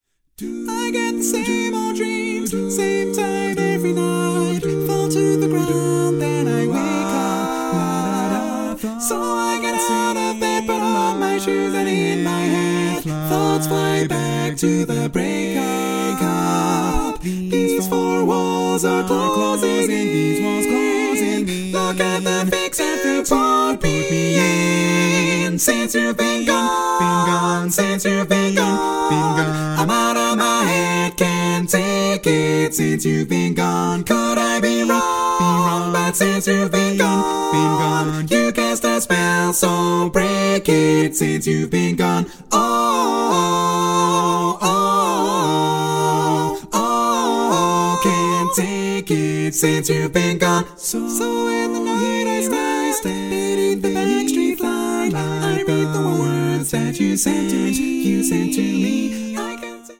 Category: Female